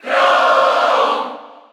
File:Chrom Cheer Spanish PAL SSBU.ogg
Chrom_Cheer_Spanish_PAL_SSBU.ogg.mp3